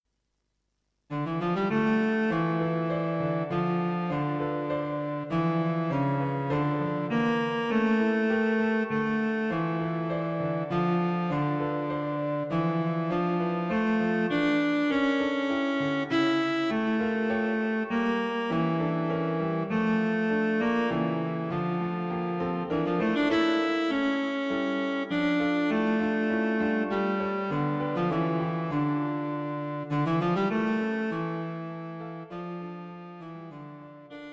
Not too difficult (grade 4/5) Running time 2 mins 18 secs.